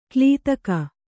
♪ klītaka